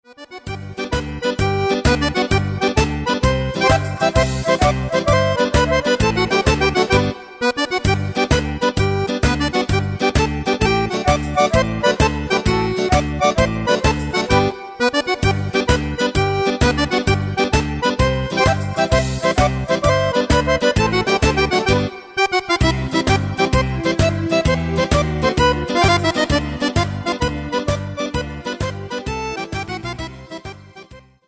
Tarantella calabrese.mp3